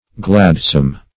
Gladsome \Glad"some\, a.